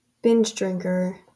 Ääntäminen
Ääntäminen US Haettu sana löytyi näillä lähdekielillä: englanti Kieli Käännökset italia avvinazzato , ubriacone , bevitore , beone saksa Suffkopp Määritelmät Substantiivi One who engages in binge drinking .